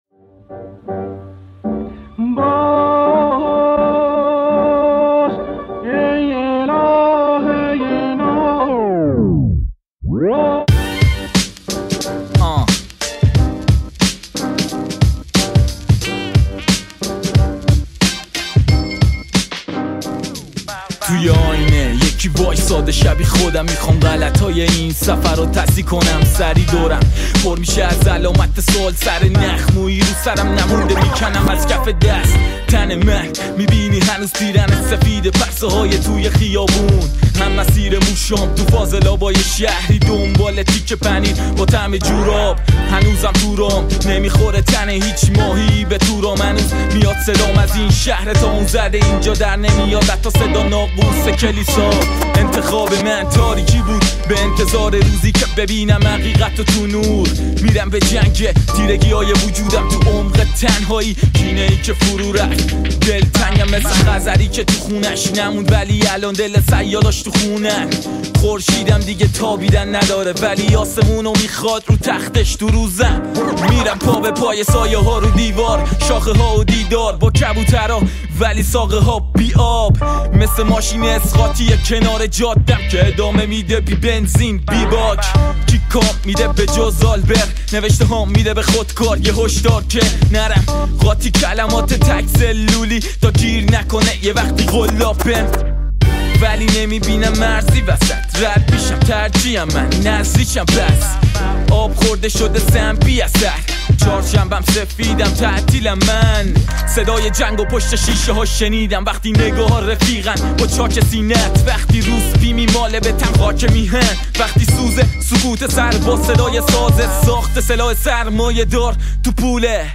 ریمیکس رپ